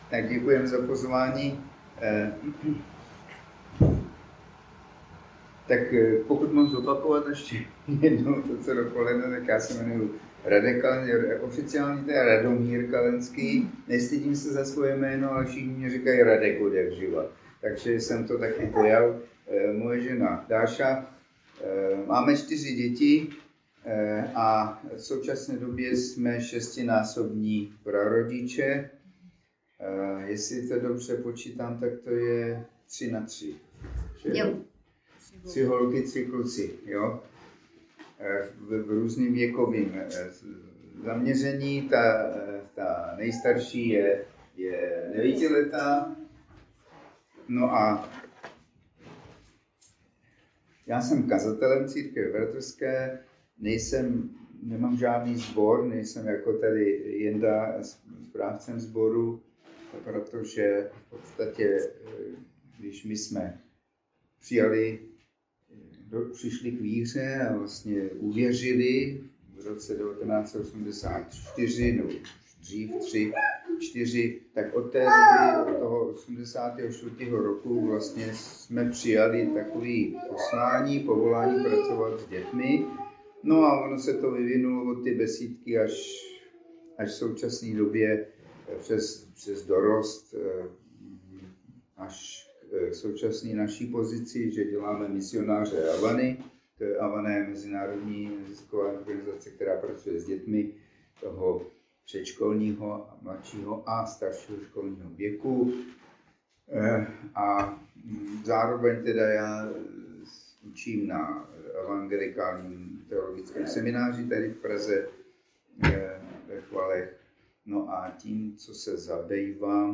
Audiozáznam přednášky.